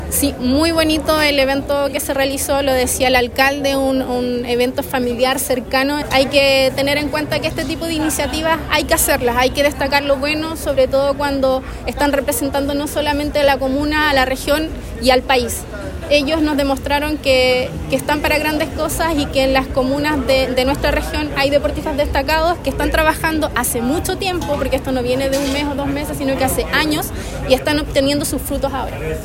En tanto la Seremi del Deporte, Carolina Urrutia, indicó que este tipo de iniciativas donde se destaca el desempeño de jóvenes deportistas permite continuar incentivando a la comunidad.